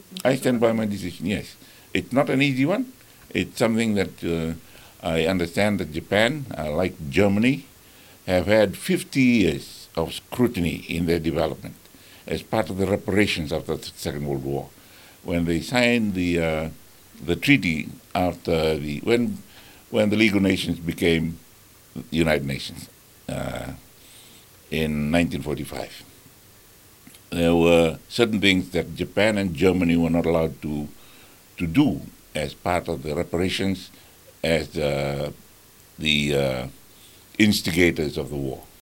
In a recent interview with FBC News, Rabuka emphasized that Japan had willingly submitted to scientific investigation and entrusted the foremost authority on nuclear accidents and radioactivity, the International Atomic Energy Agency, to oversee the process.